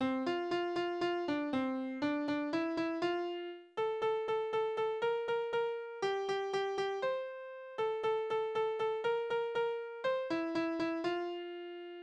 Spielverse:
Tonart: F-Dur
Taktart: 2/4
Tonumfang: Oktave
Besetzung: vokal